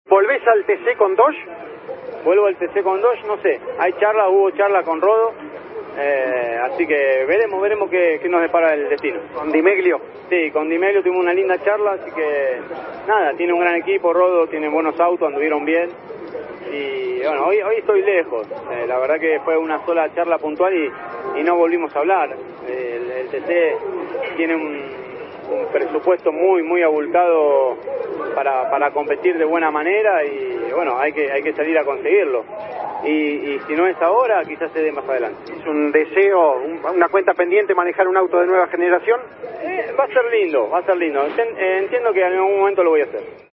En diálogo con Campeones, Leonel Pernía confirmó que se mueve para volver al Turismo Carretera en 2026.